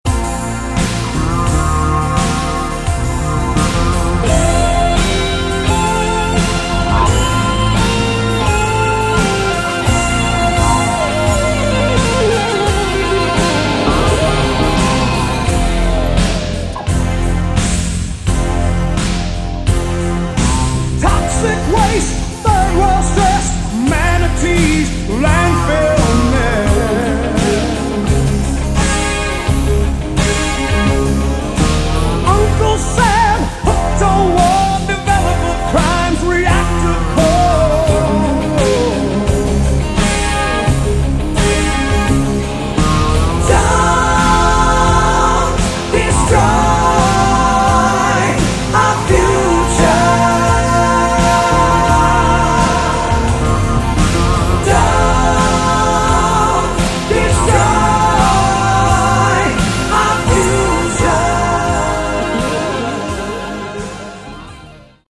Category: Melodic Rock
vocals
keyboards, guitar
bass
drums